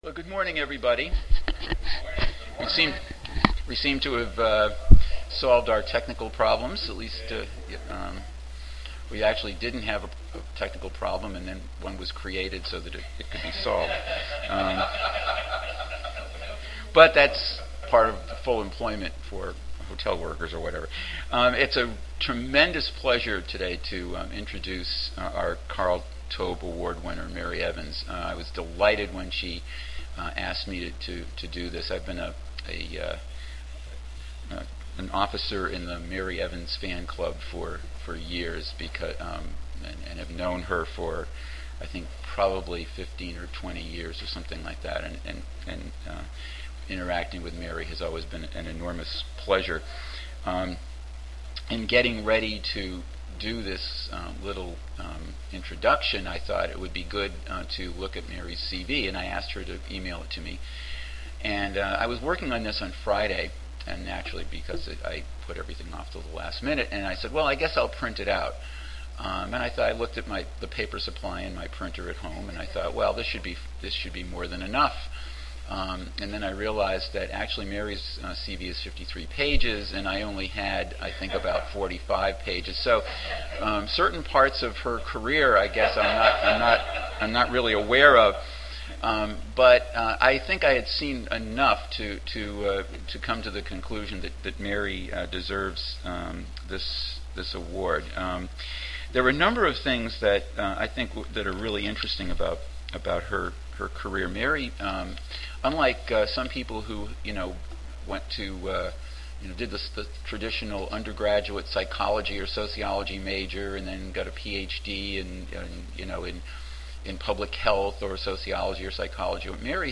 Oral Session